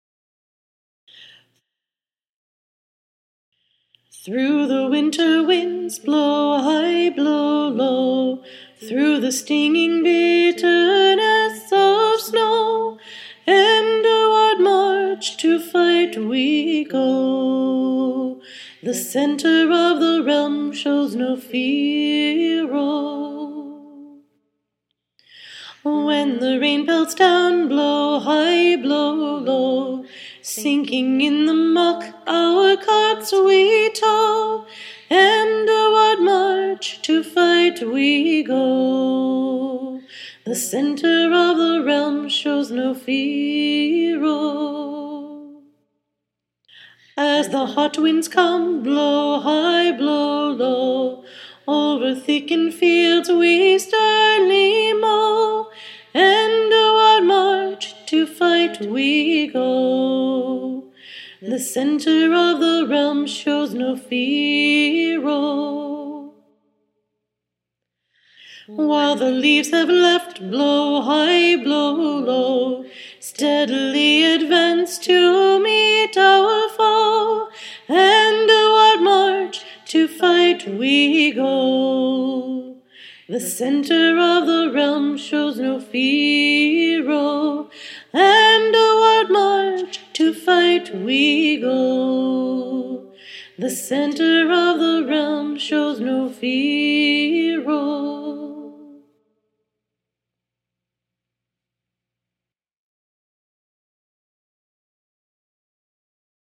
Fighters of the Center of the Realm was written to be a marching song for the fighting forces of Endewearde. It speaks of all our lovely seasons, and marching in them.